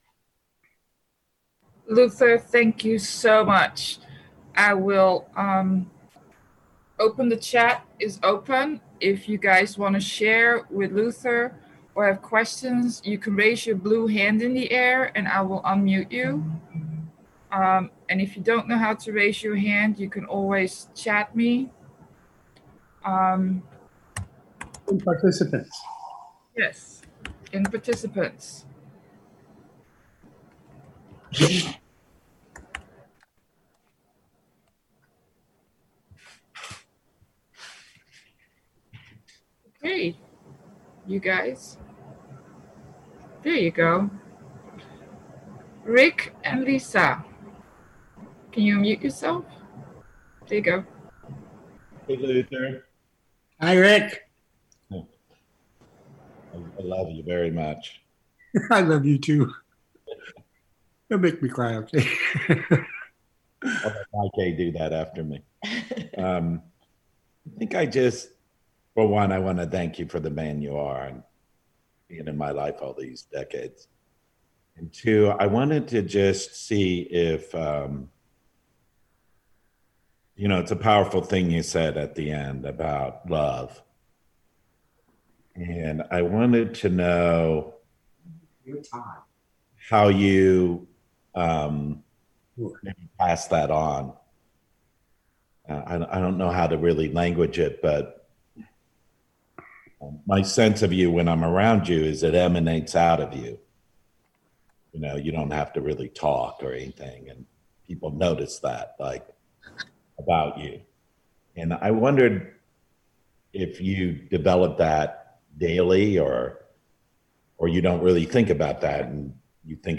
AWB Workshop - Emotional Sobriety